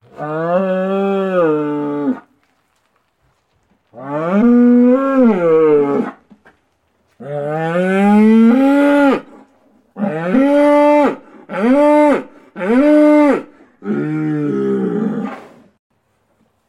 cow-sound